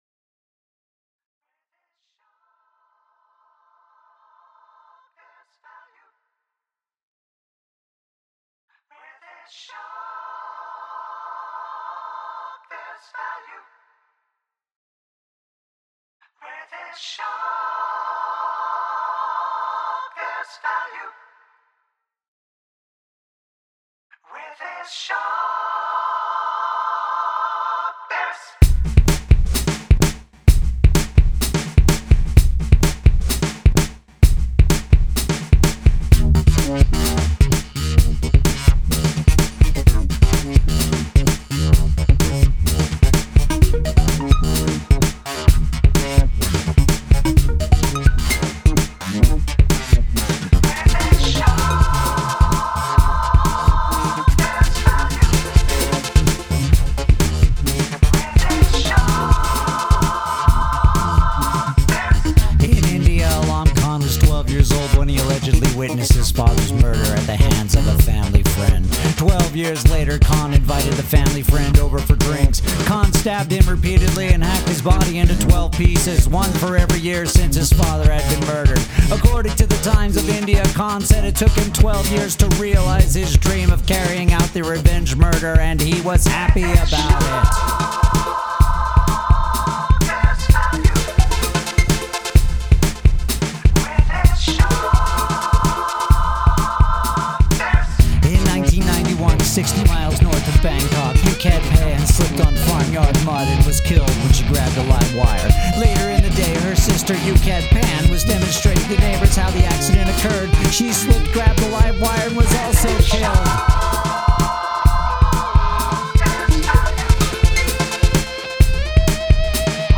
Make a song with quiet and loud parts -- like every Pixies song you love.
The spoken-word stories are interesting (and speaking fast was a good idea), but I didn’t care that much for the underlying music.
A great groove/vibe counts for a lot.